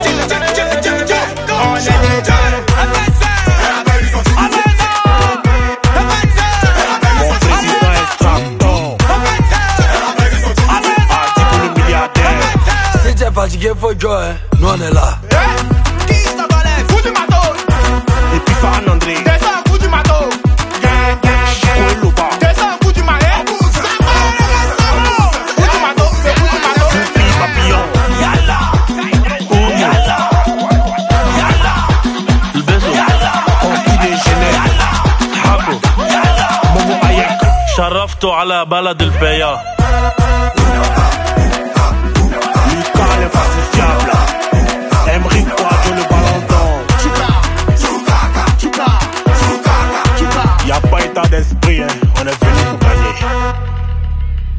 Surround sound challenge